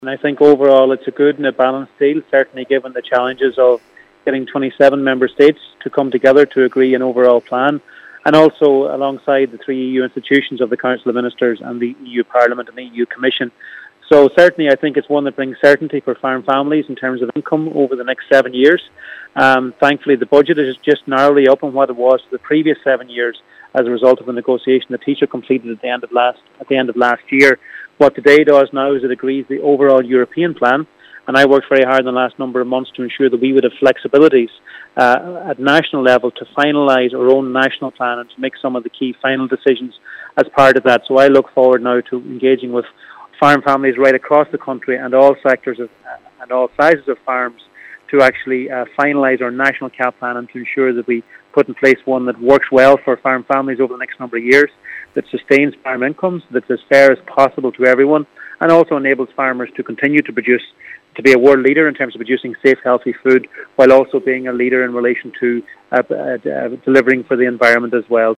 Minister Charlie McConalogue says he’s happy with the deal that’s been done, and is promising widespread consultation on the Irish national plan…………..